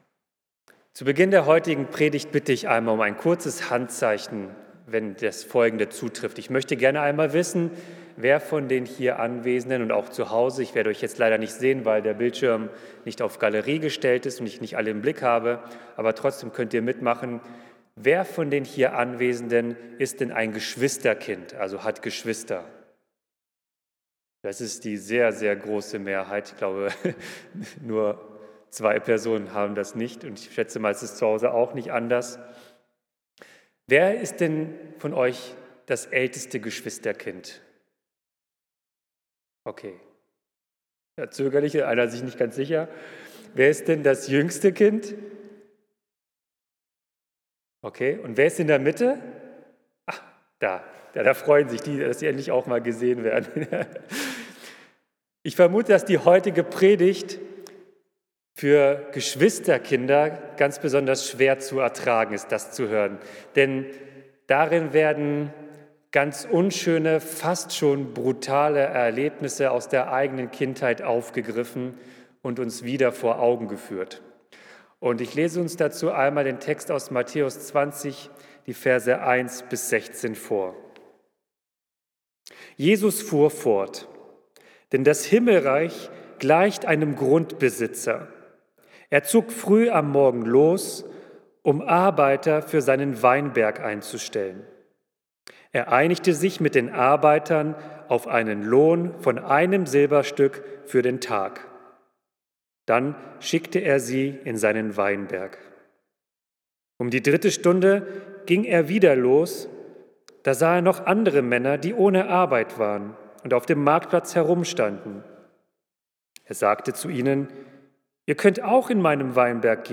Ein neuer Maßstab von Gerechtigkeit - Predigt zu Matthäus 20, 1-16 | Bethel-Gemeinde Berlin Friedrichshain